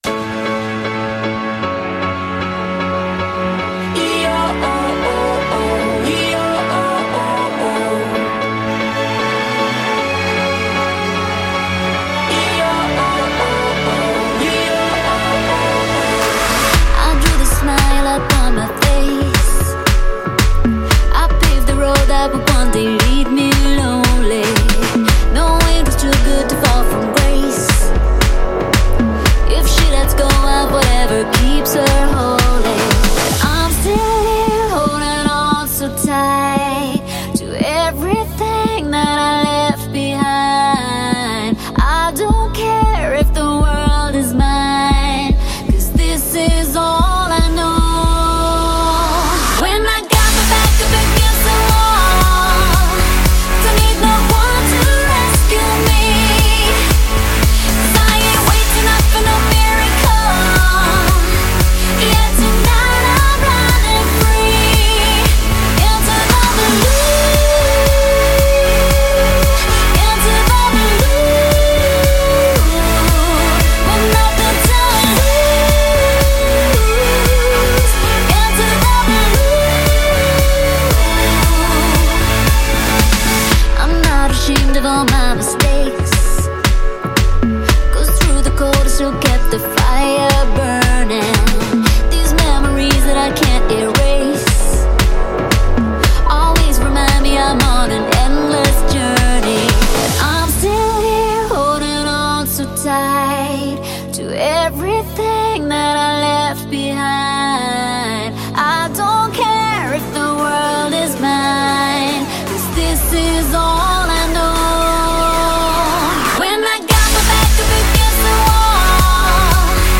Главная » Файлы » Pop